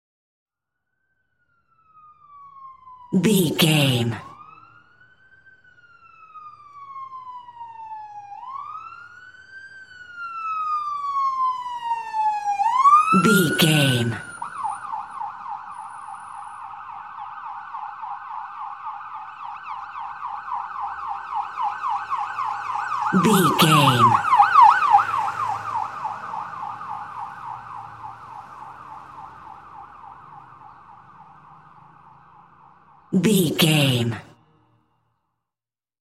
Ambulance Ext Passby Large Short Siren
Sound Effects
urban
chaotic
emergency